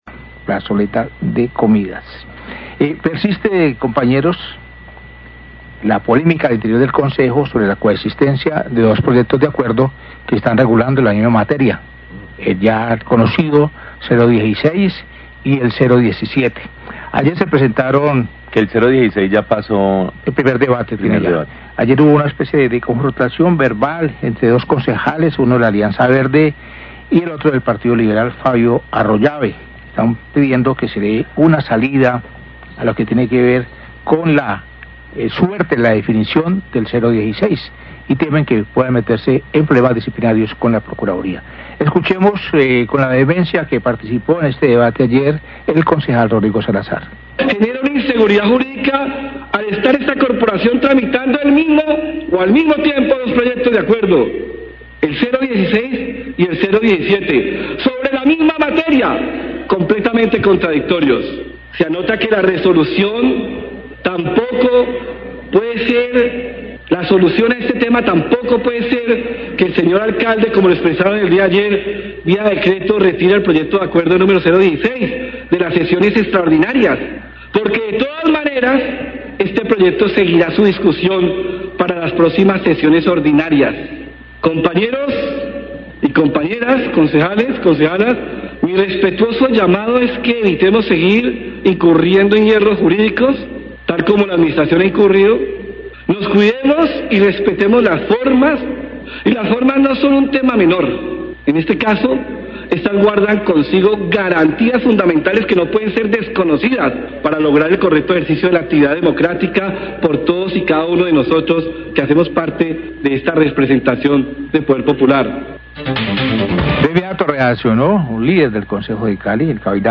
Radio
Presentan audios de los concejales Rodrigo Salazar de la Alianza verde y, de Fabio Arroyabe del partido Liberal.